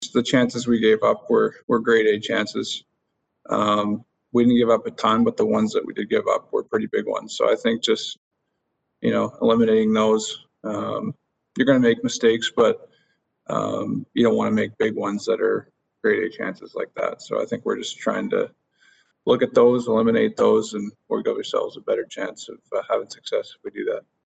Penguins captain Sidney Crosby says the team’s mistakes on Wednesday were costly.